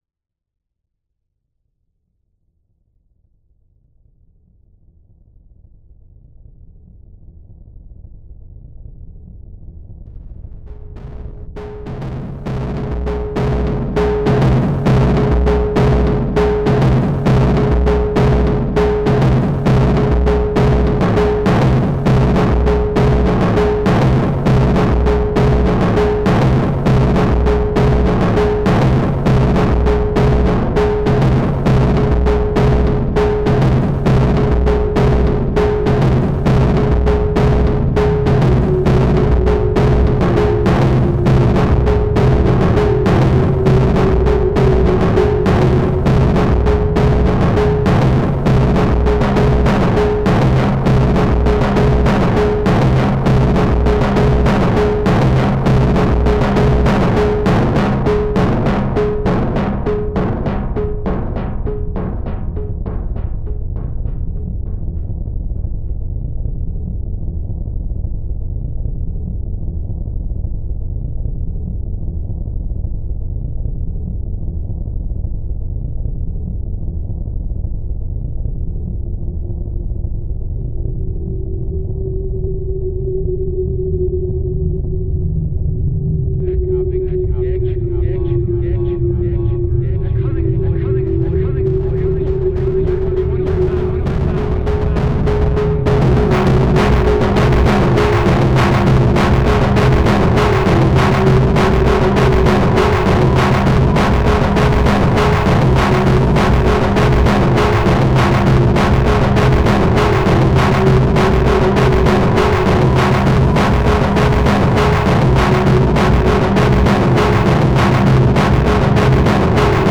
Genre: dark ambient; noise; drones